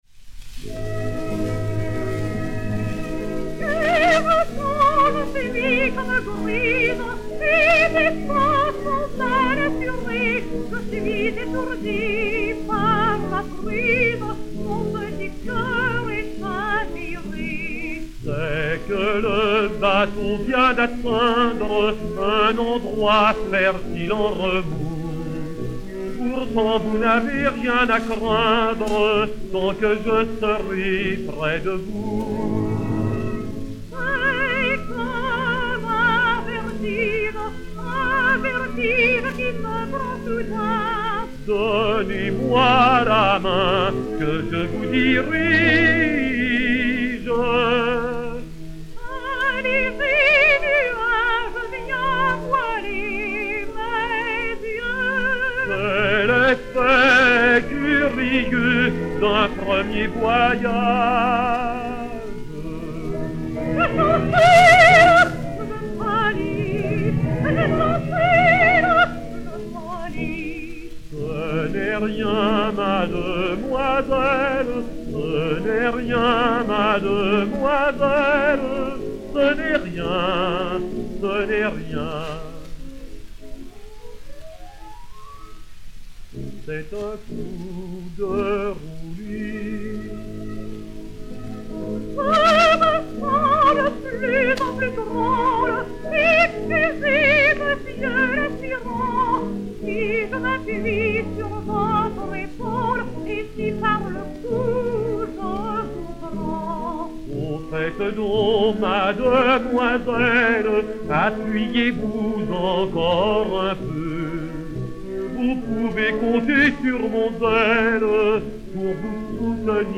et Orchestre dir.